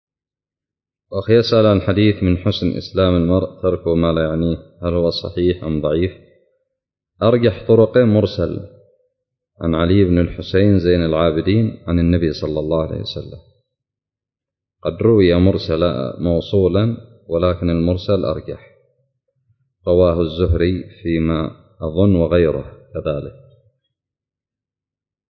سؤال قدم لفضيلة الشيخ حفظه الله